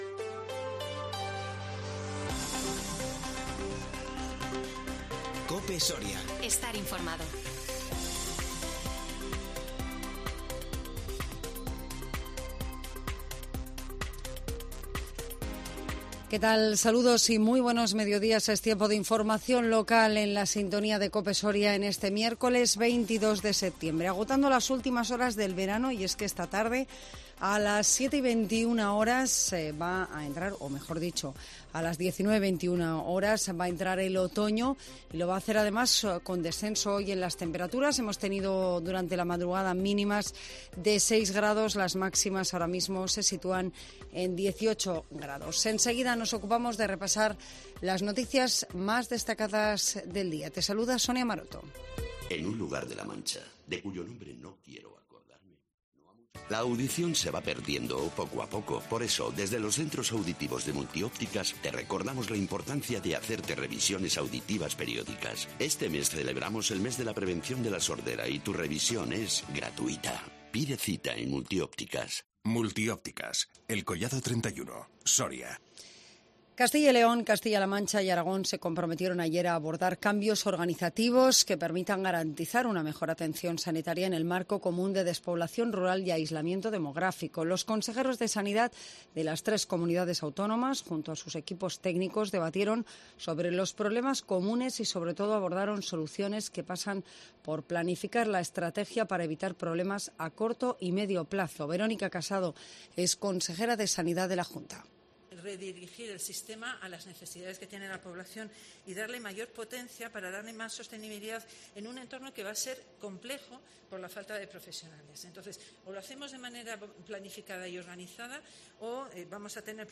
INFORMATIVO MEDIODÍA 22 SEPTIEMBRE 2021